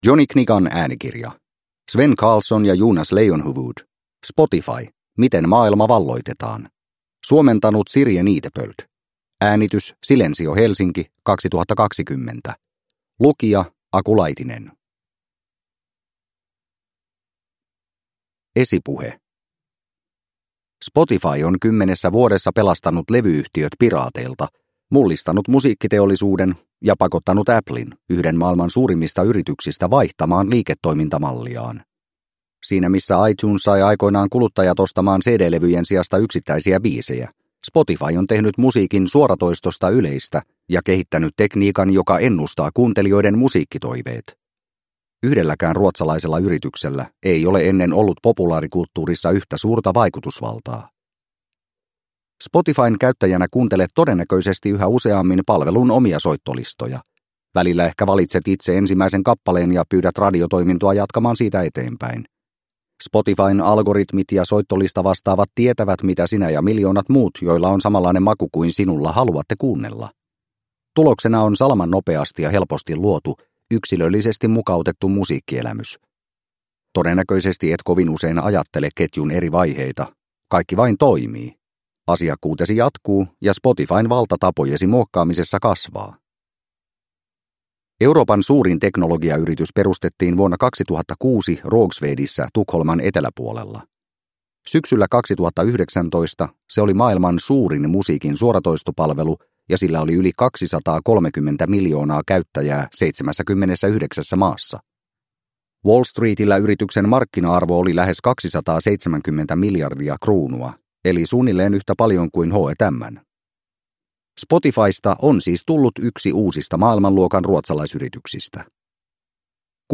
Spotify – Ljudbok – Laddas ner